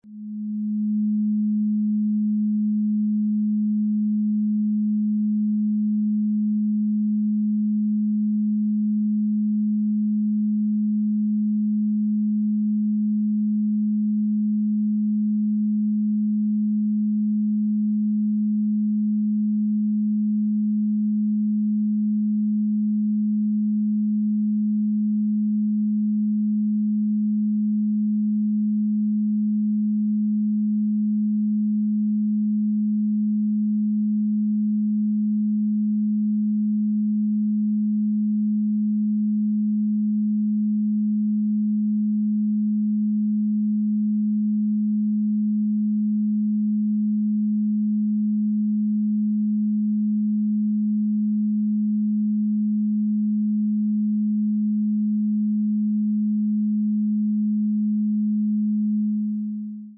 The audio frequency samples below were recorded with the app.
211.44 Hz : Frequency of Neptune.
audio_211_44Hz.mp3